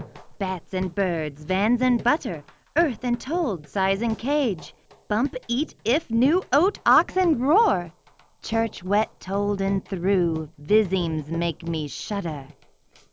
Recovered signal (NLMS)
• NLMS appears to be better in first case while RLS is better in second case.